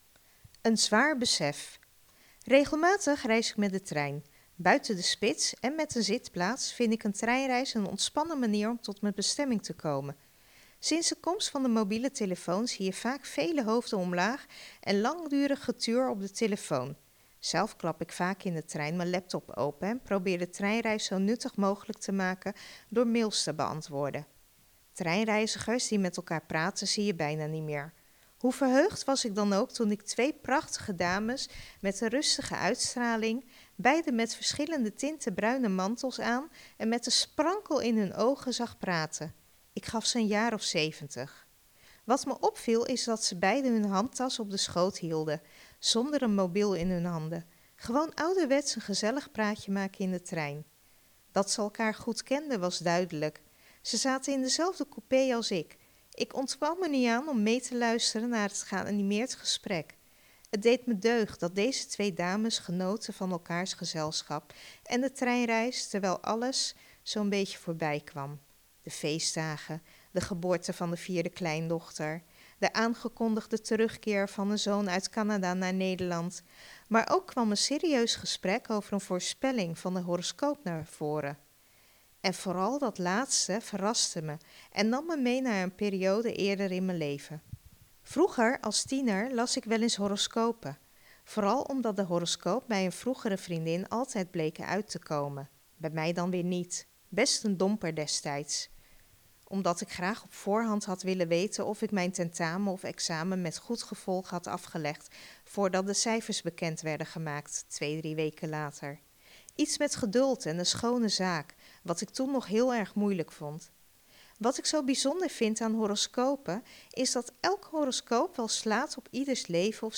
Kletskoek wordt iedere vrijdagochtend live� tussen 10 en 13 uur vanuit de studio�van Radio Capelle uitgezonden.